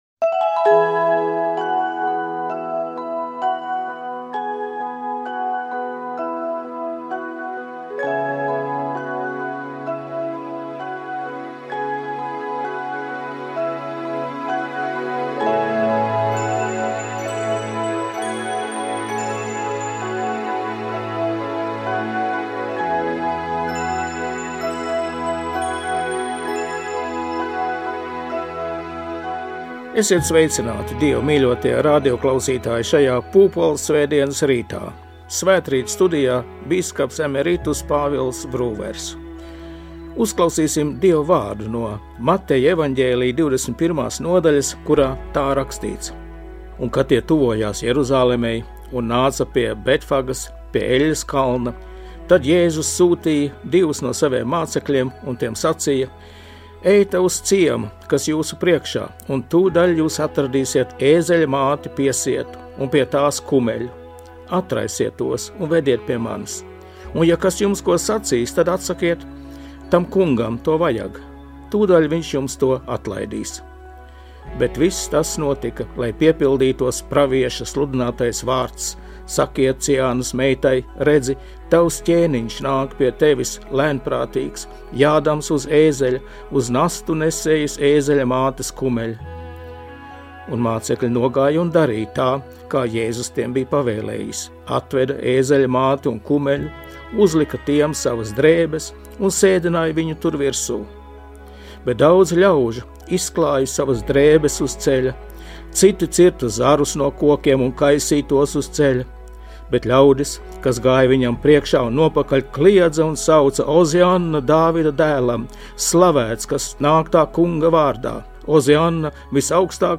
Svētrīts. Pūpolsvētdienā studijā bīskaps emeritus Pāvils Brūvers